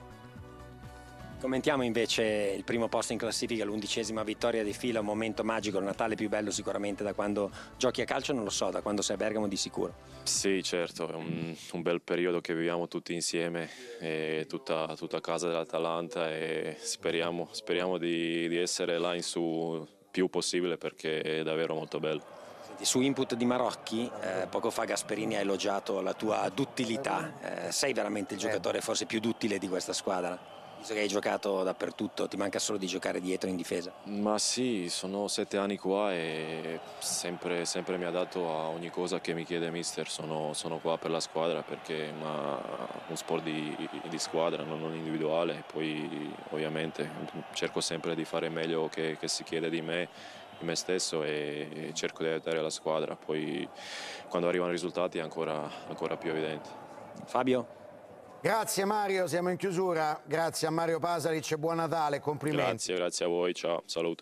Piu' tardi in conferenza stampa dopo la vittoria 3-2 contro l’Empoli, Mario Pasalic ha evidenziato l'importanza del gruppo e dell'esperienza nel successo dell'Atalanta. Ha descritto la partita come difficile, sottolineando le difficoltà del primo tempo e la reazione della squadra nella ripresa.